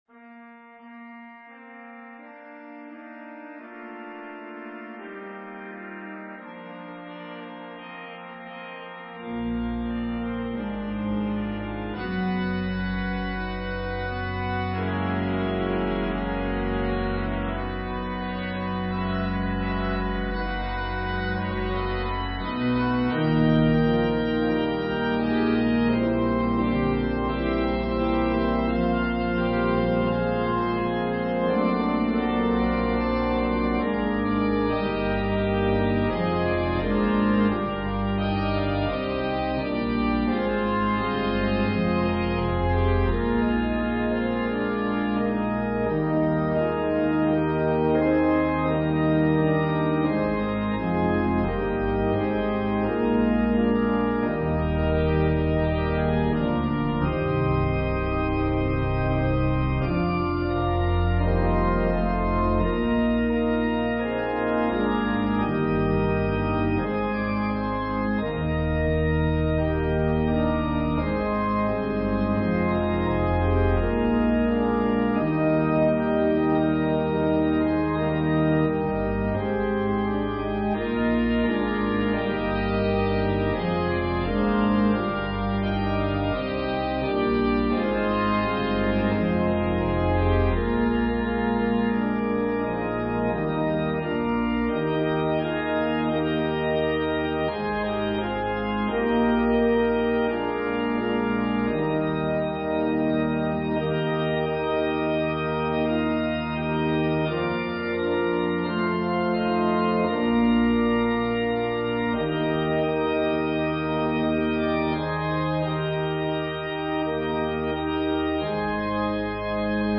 An organ solo version
Voicing/Instrumentation: Organ/Organ Accompaniment